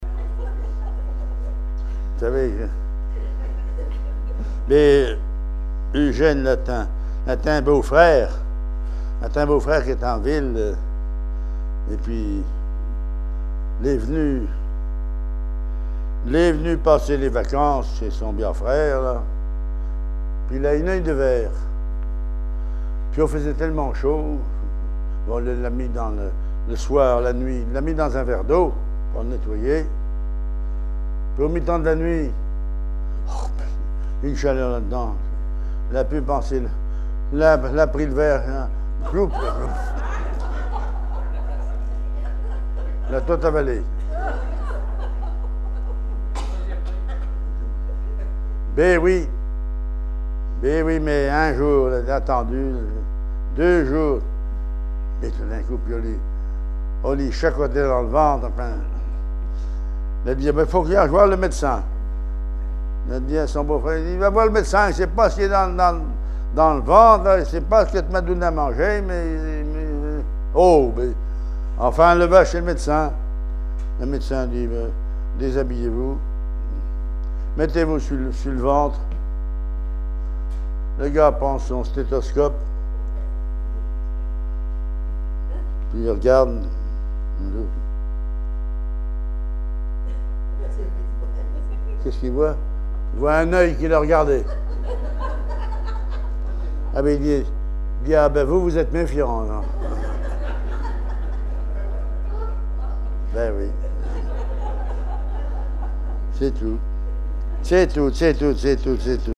Langue Patois local
Genre sketch